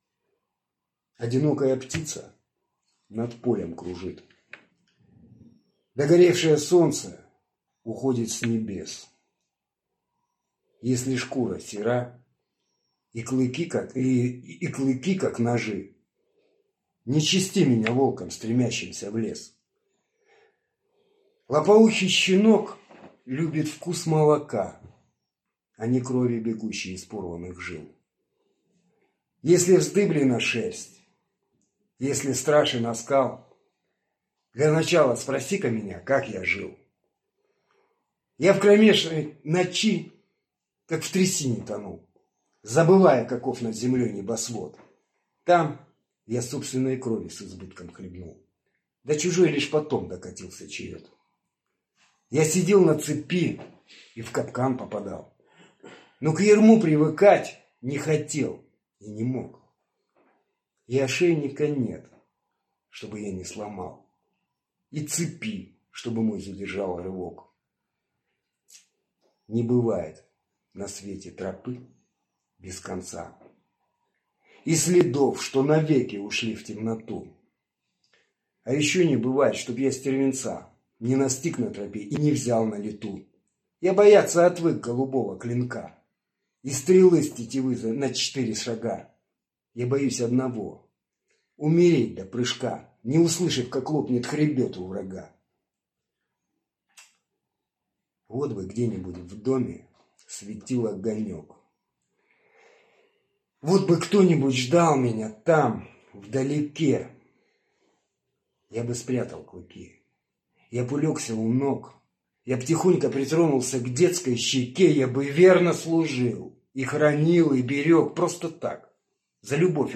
stihotvorenie.mp3